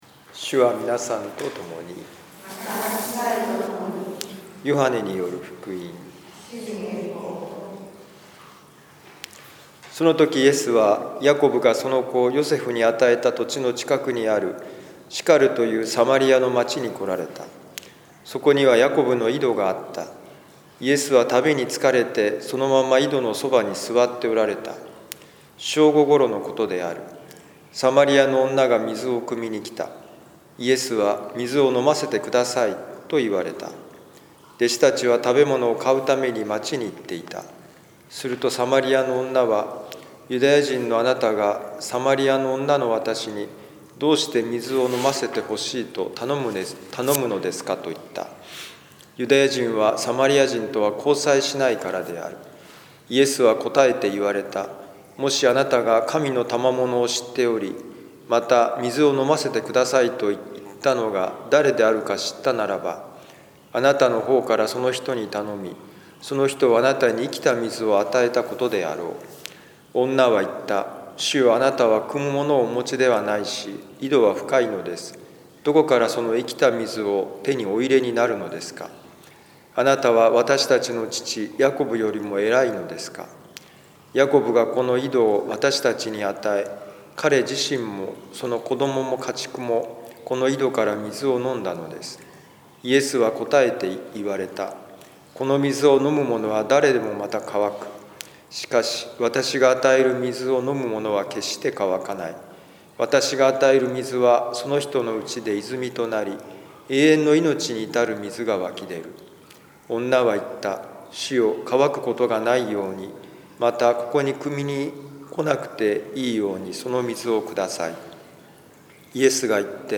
ヨハネ4章5-15,19b-26,39a,40-42節「霊と真理でこの時を生き抜く」2020年3月14日ザビエル聖堂での小さなミサ
ヨハネ4章5-15,19b-26,39a,40-42節「霊と真理でこの時を生き抜く」2020年3月14日ザビエル聖堂での小さなミサ この福音書朗読とお説教ではこんなお話が聞けます サマリアの女は5回の結婚を繰り返し、現在は6人目の恋人と同棲中でした サマリアの女と自分は違う、と思っていませんか？